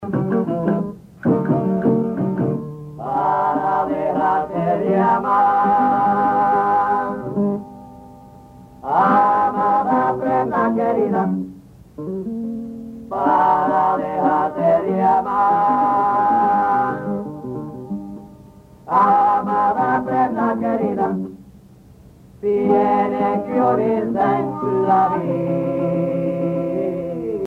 Parranda
Pièces musicales tirées de la Parranda Tipica Espirituana, Sancti Spiritus, Cuba
Pièce musicale inédite